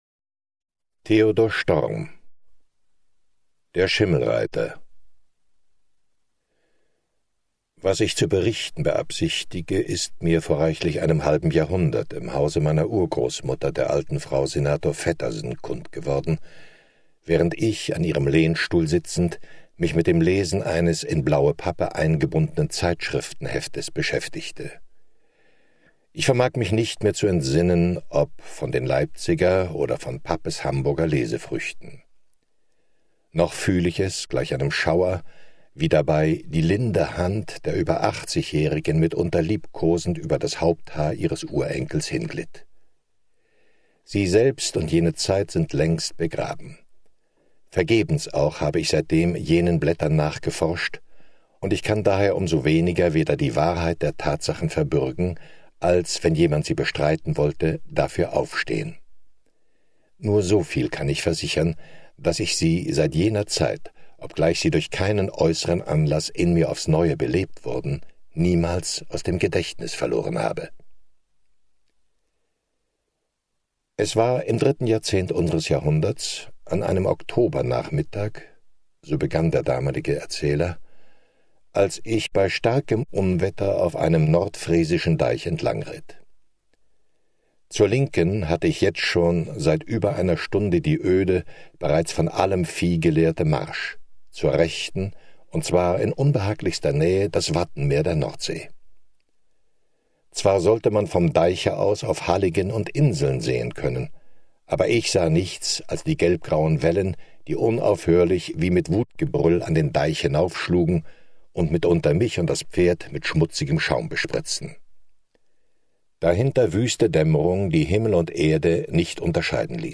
Storm.mp3